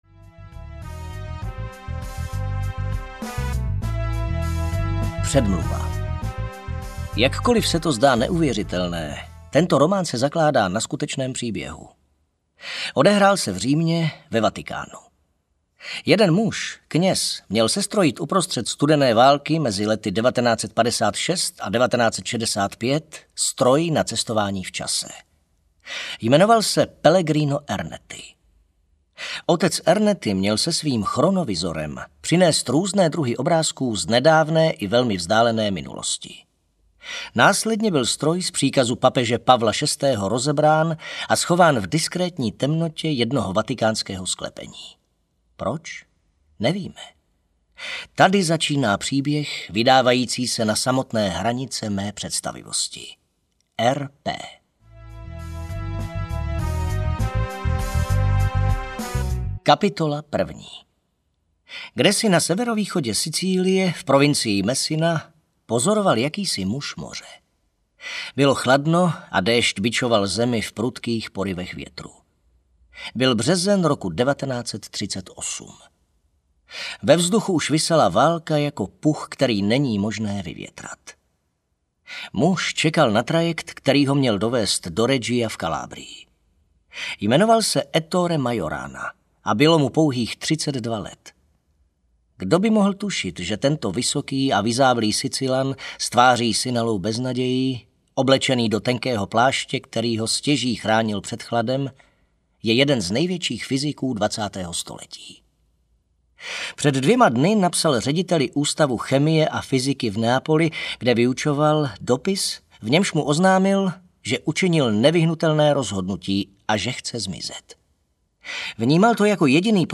Ernettiho stroj audiokniha
Ukázka z knihy